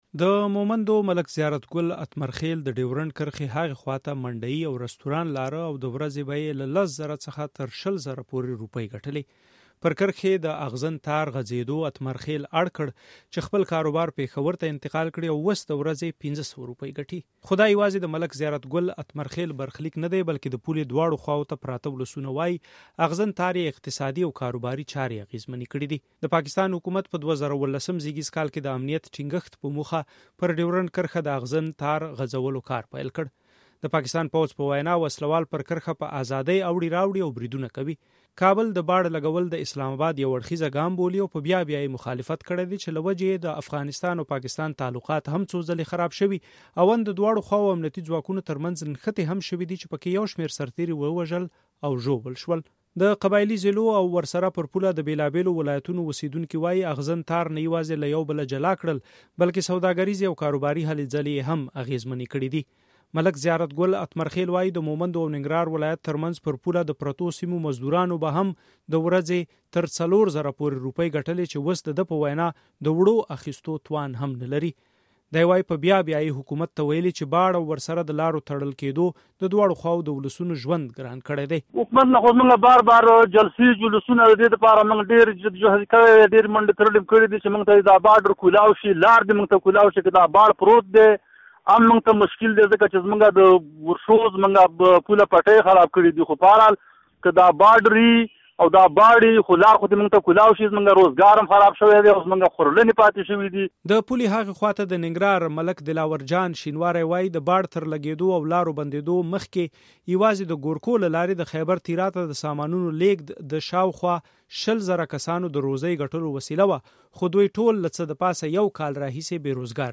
د کرښې دواړو خواوو ته پراته خلک وايي چې د اغزن تار د لګېدو او پر پوله د تګ راتګ محدودېدو له وجې یې اقتصادي زیانونه ګاللي او کاروبارونه یې اغېزمن شوي دي. پر ډېورنډ کرښه د باړ لګېدو د اقتصادي اثراتو په اړه رپورټ دلته واورئ.